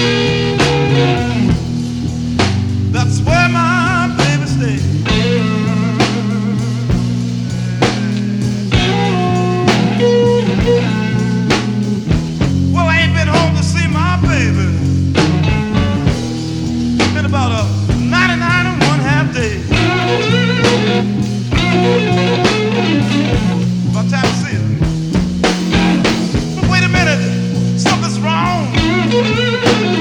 Enregistré en public le 18 mai 1968
Rock et variétés internationales